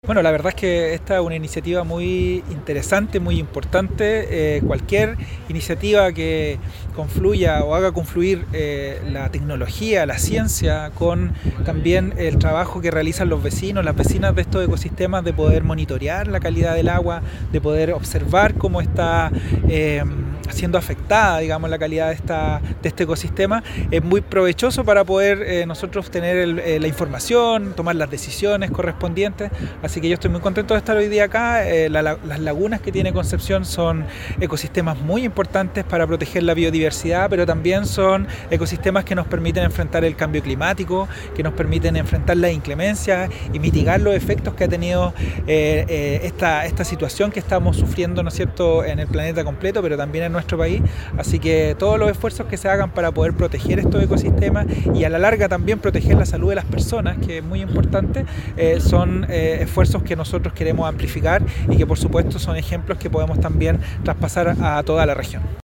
Por su parte, el seremi del Medio Ambiente, Pablo Pinto Valenzuela, relevó las labores que viene realizando la Municipalidad de Concepción para el cuidado y protección de las lagunas.